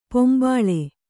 ♪ pombāḷe